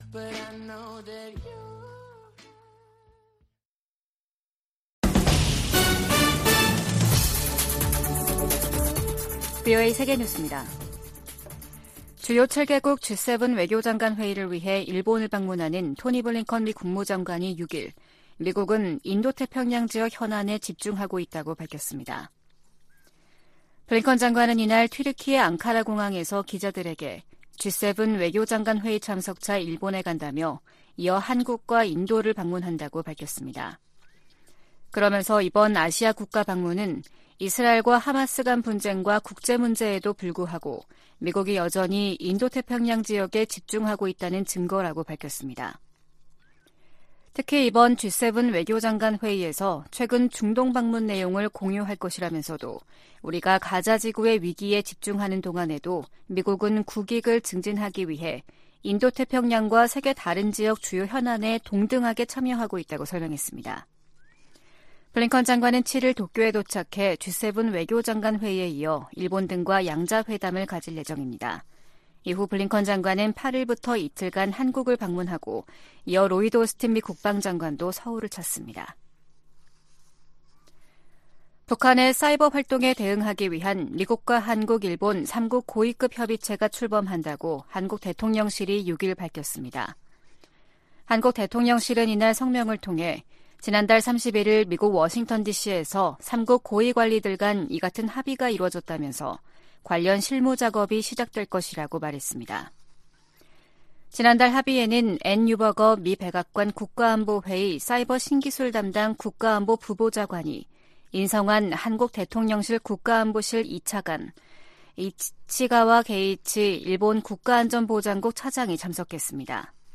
VOA 한국어 아침 뉴스 프로그램 '워싱턴 뉴스 광장' 2023년 11월 7일 방송입니다. 미 국방부는 북한이 미 대륙간탄도미사일 '미니트맨3' 시험발사를 비난한 데 대해 북한의 군사적 위협을 지적했습니다. 미 상원의원들이 북한과 러시아 간 군사 협력 확대가 전 세계를 위협한다며 단호한 국제적 대응을 촉구했습니다. 유엔 식량농업기구(FAO)는 17년재 북한을 외부의 식량 지원 필요국으로 지정했습니다.